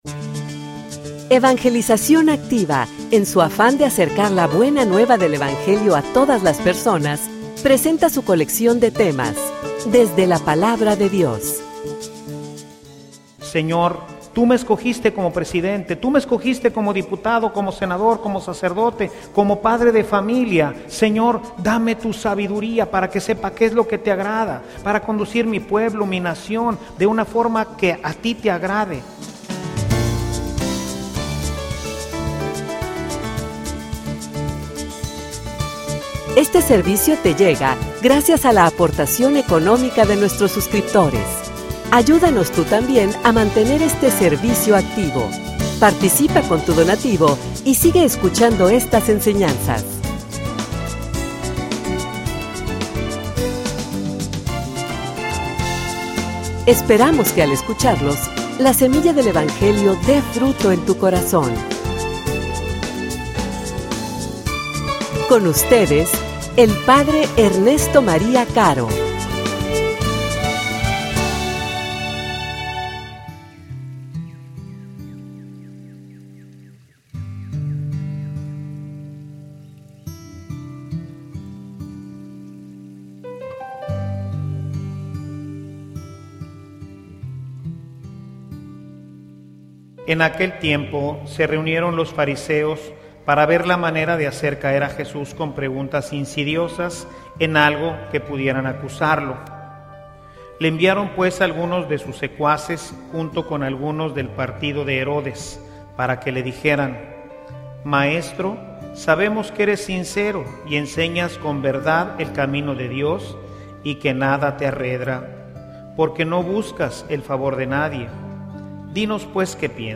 homilia_Ningun_poder_esta_por_encima_de_Dios.mp3